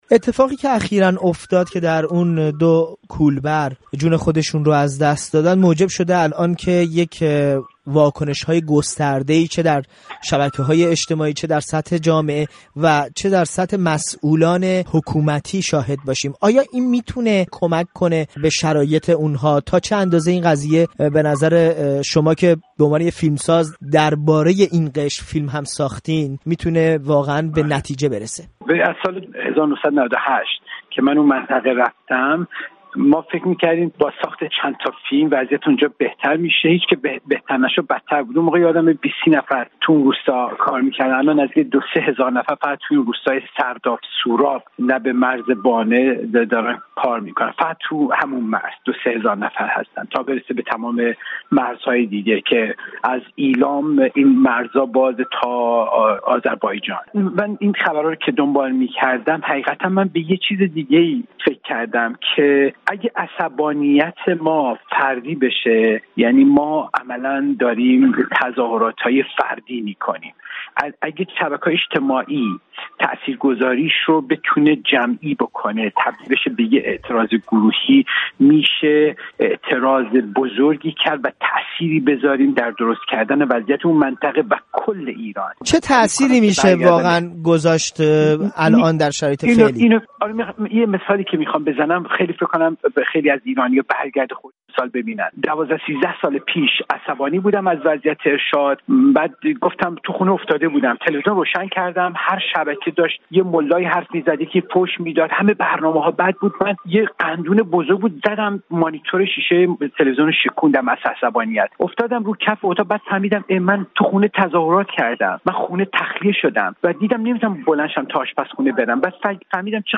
گفت‌وگو با بهمن قبادی، فیلمساز ایرانی، درباره کشته شدن دو کولبر نوجوان